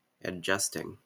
Ääntäminen
Tuntematon aksentti: IPA : /əˈd͡ʒʌstɪŋ/